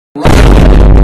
Memes
Vine Boom Bass Boosted Man